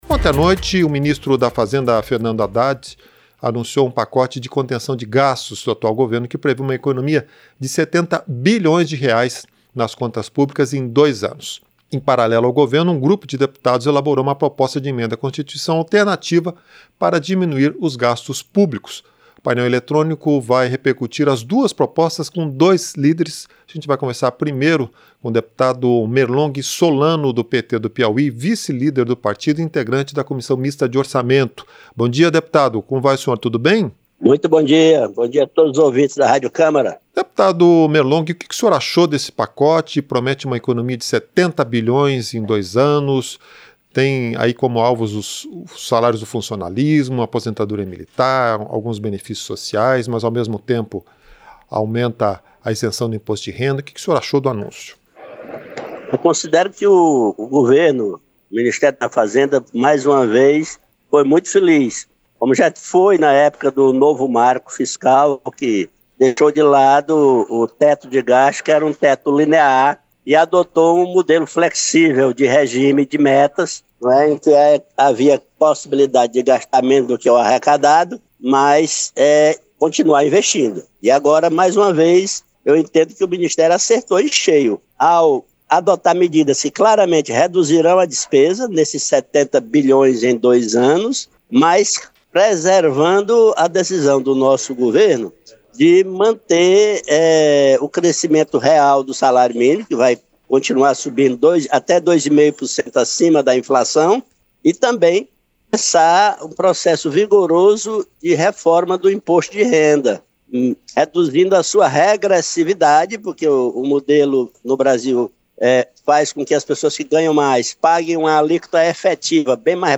Entrevista - Dep. Merlong Solano (PT-PI)